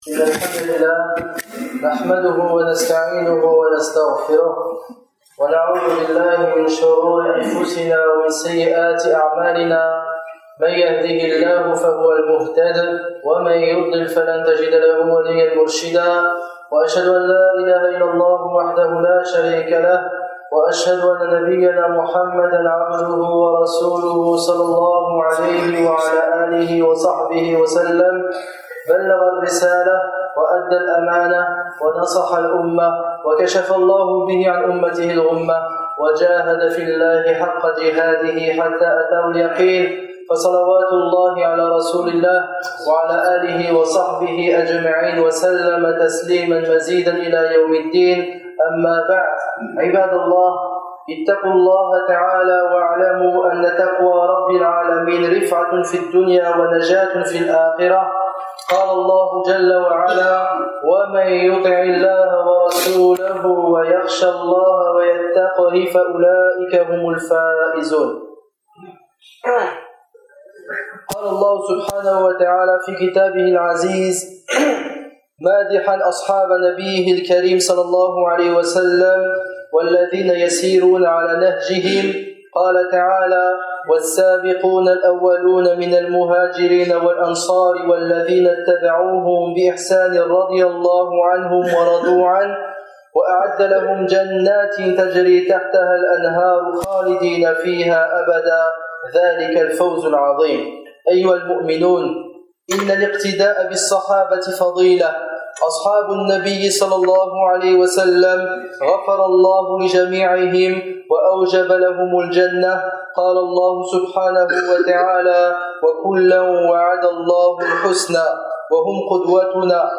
Ce sermon relate quelques récits des jeunes compagnons dans l’histoire des débuts de l’islam,